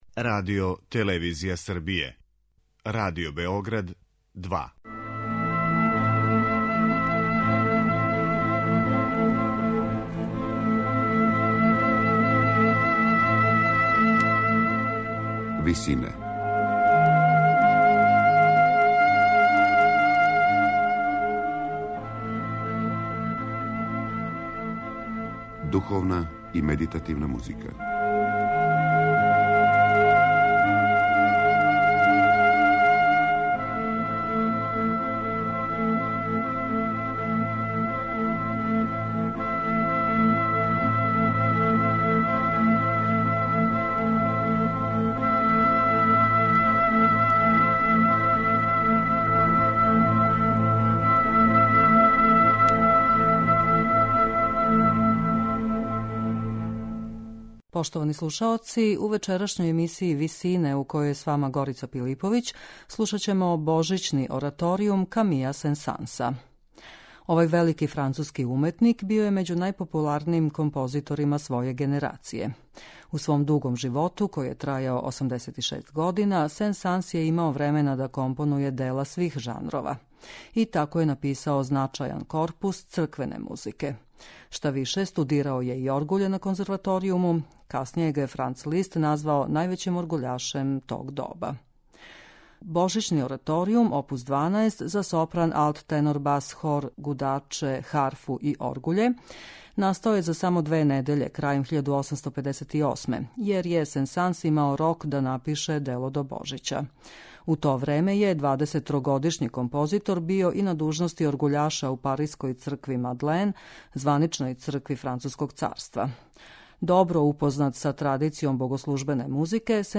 Духовна музика Камија Сен-Санса
Па ипак, композиција која се састоји од десет нумера, показује необичан распон извођачких пракси - од соло речитатива до оперских епизода.